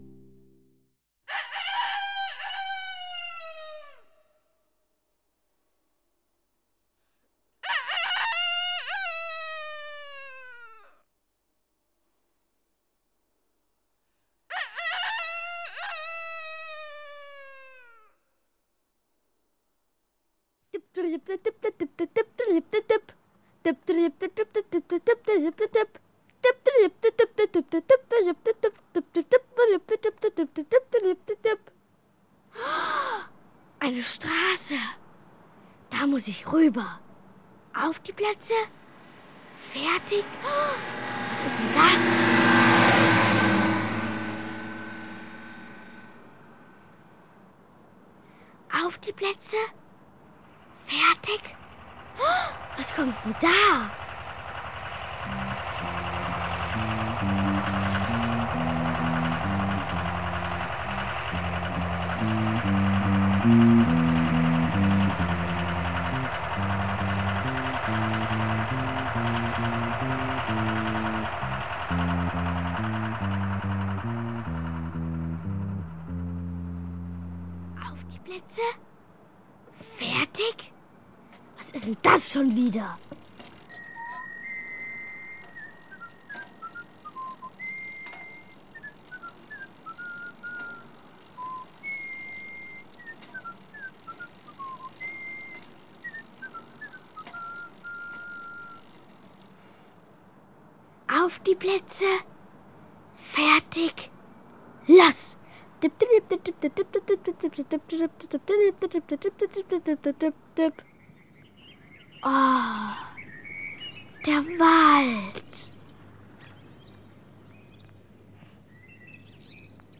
Zwei musikalische Hörspiele